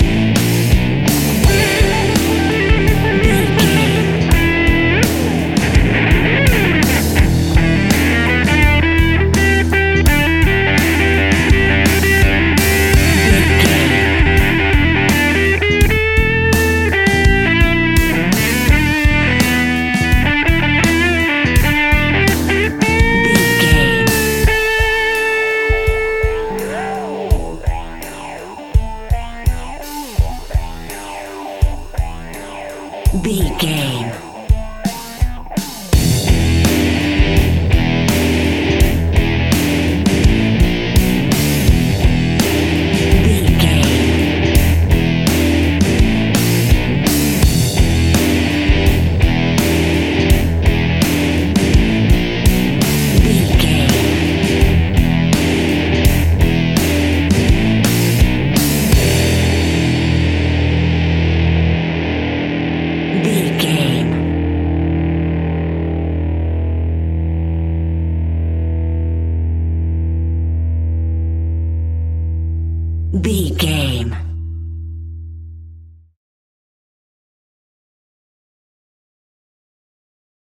Fast paced
Aeolian/Minor
hard rock
blues rock
rock instrumentals
Rock Bass
heavy drums
distorted guitars
hammond organ